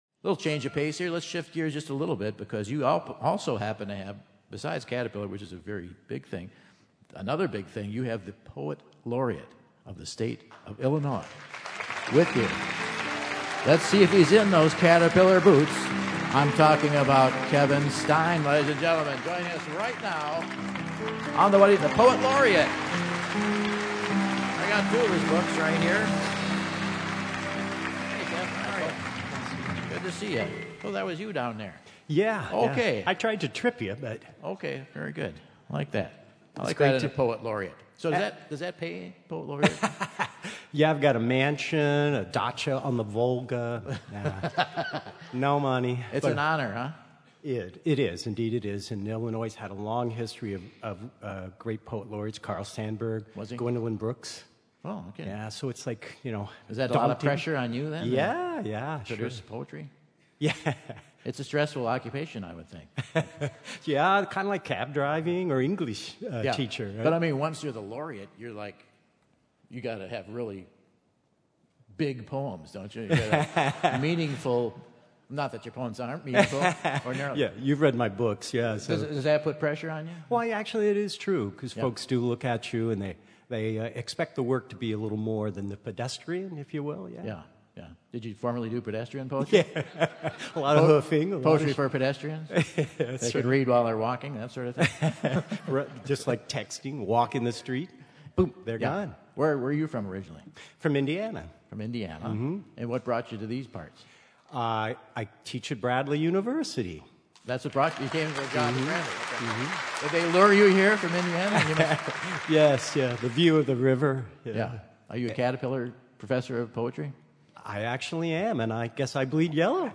Illinois Poet Laureate Kevin Stein, shares a little of what poetry is to him and then reads a few of his works!